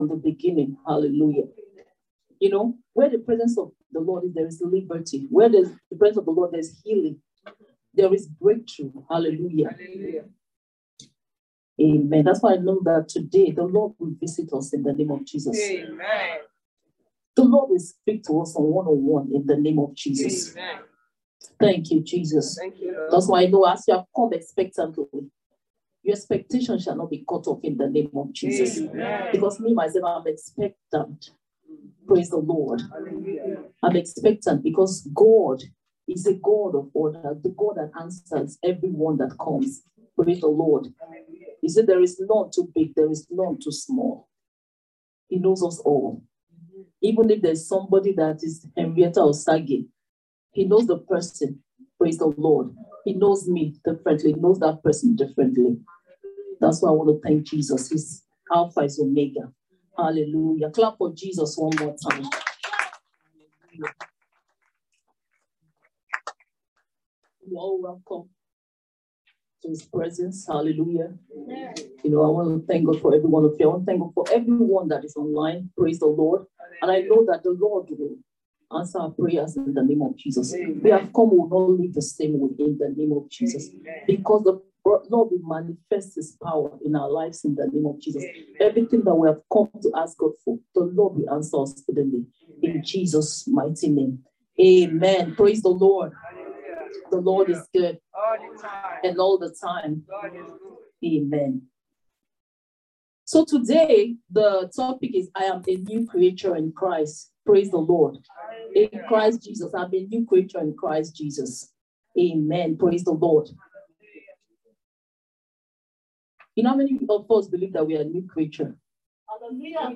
February-2022-Anointing-Service.mp3